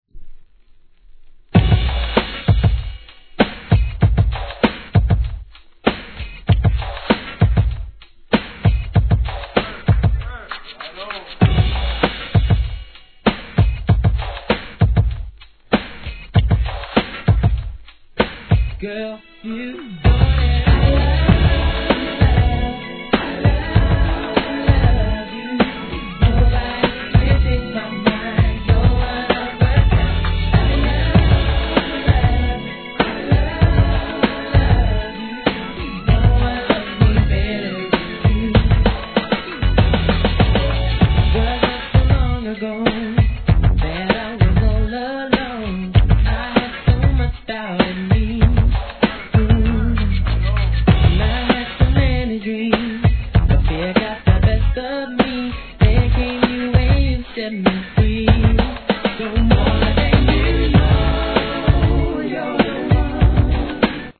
HIP HOP/R&B
'90s正統派R&Bのデビュー傑作!